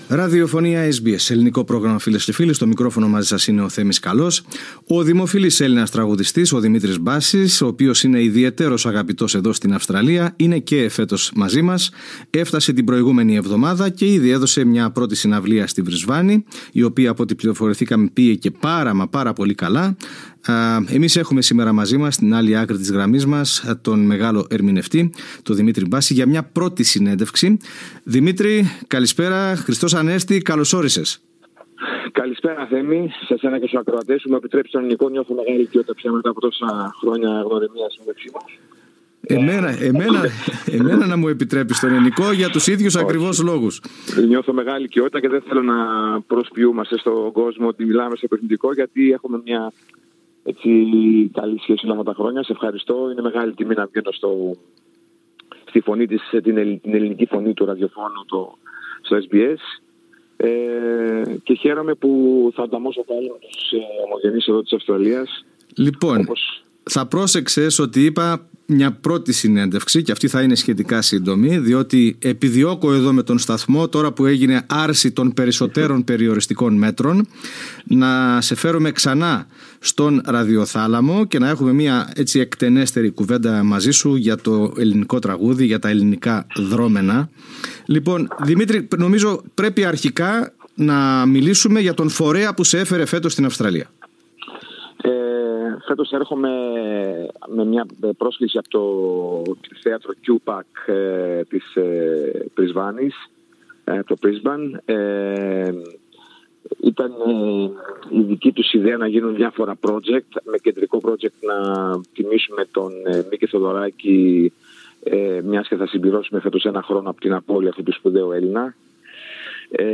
Στη συνέντευξή του στο SBS Greek, ο Δημήτρης Μπάσης μίλησε για το εμβληματικό έργο του Θεοδωράκη, και τη συνεργασία του με τον μεγάλο μουσικοσυνθέτη.